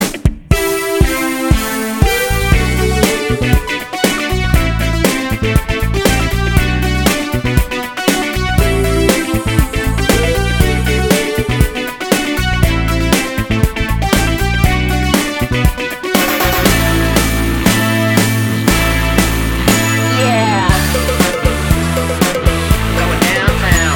Minus All Guitars Pop (2000s) 3:22 Buy £1.50